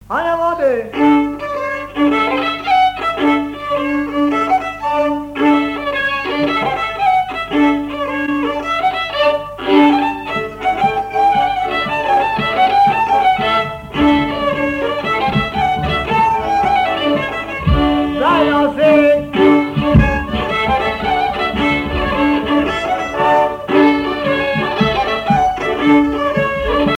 danse : branle : avant-deux
Pièce musicale inédite